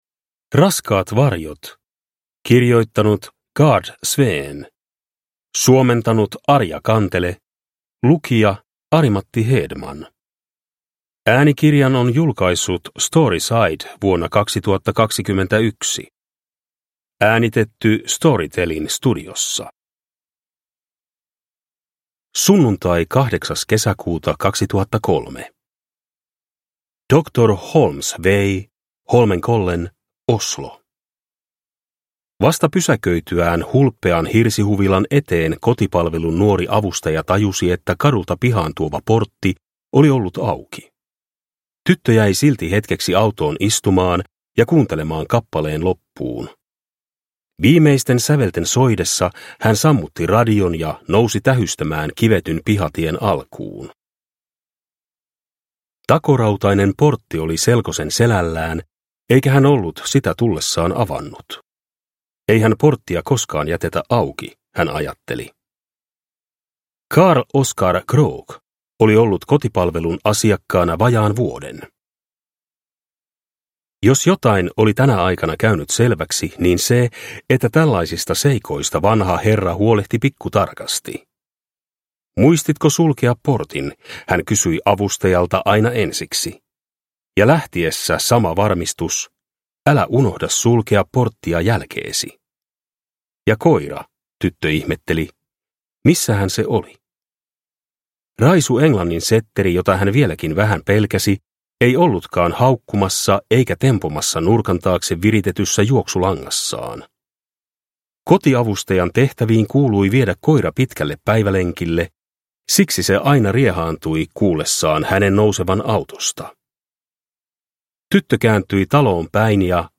Raskaat varjot – Ljudbok – Laddas ner